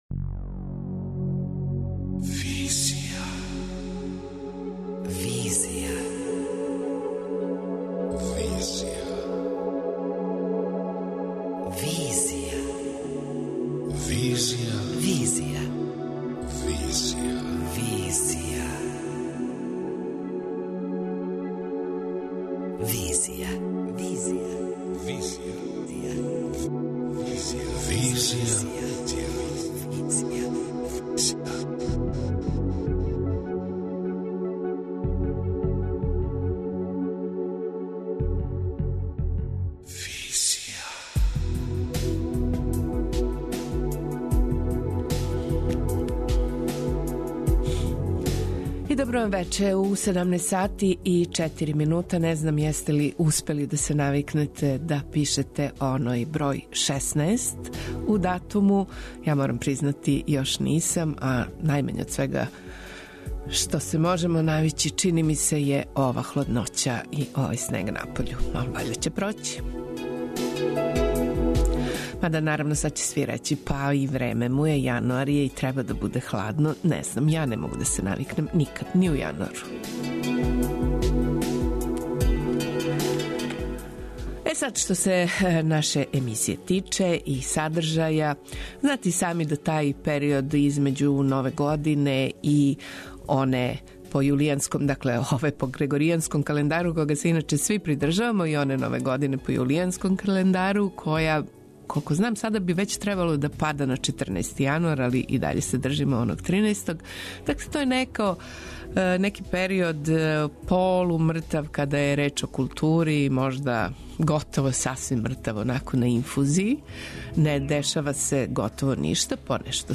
Социо-културолошки магазин, који прати савремене друштвене феномене.